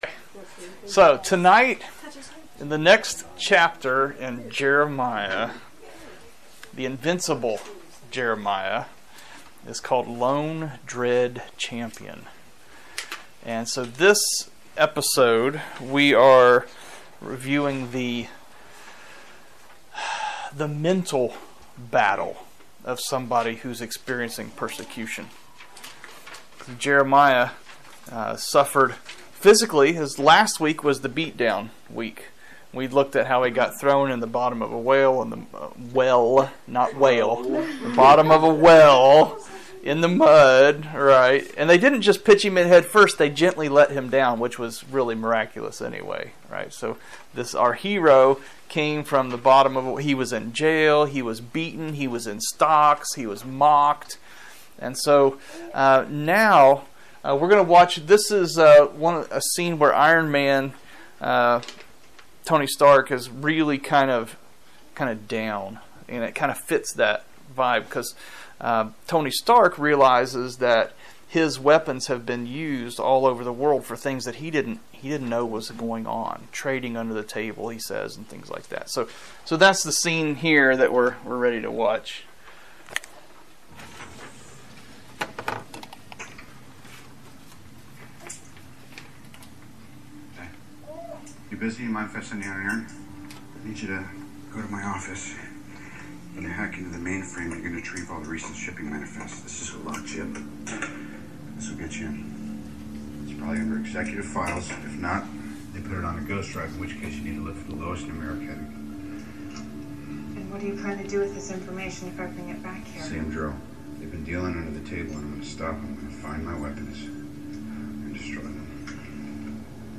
Sermons | New Song Community Church